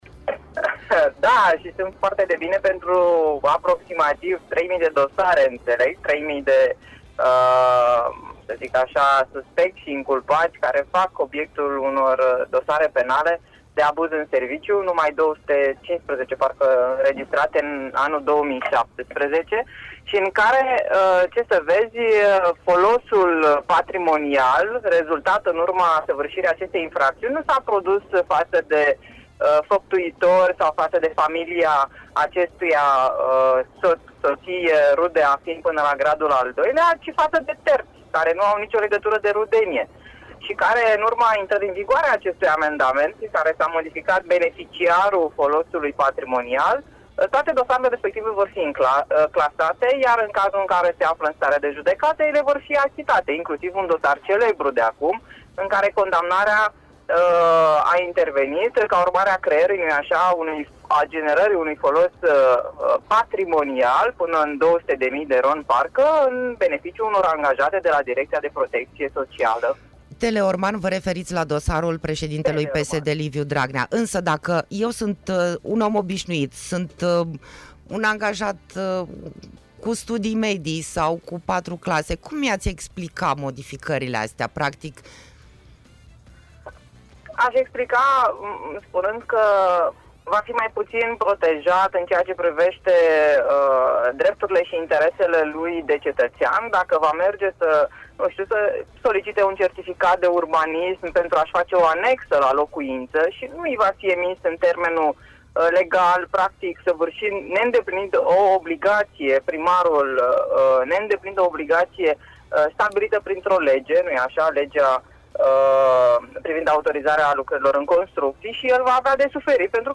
Senatorul PNL Iulia Scântei a evidențiat cele mai dure implicații legate de abuzul în serviciu într-un interviu RadioHit